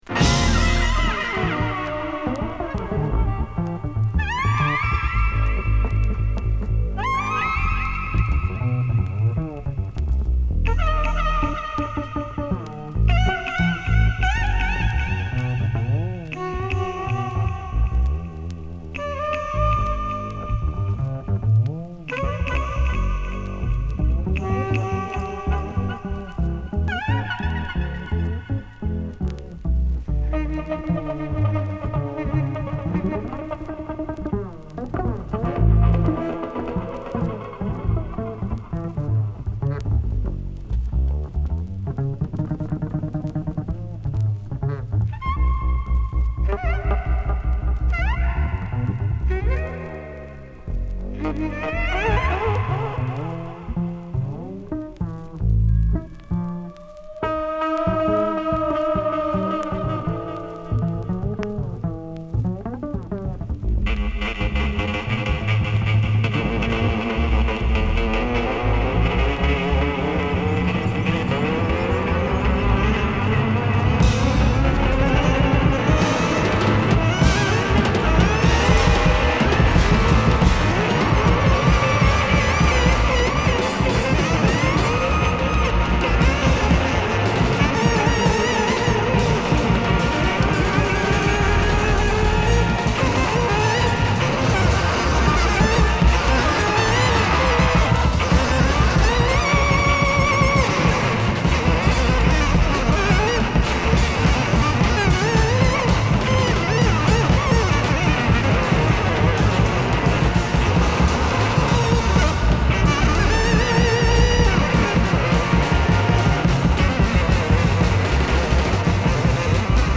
中盤からピッチダウンしての展開も抜群のカッコ良さ！他にもエフェクト処理でトバすドープ・ジャズ・ファンクを収録！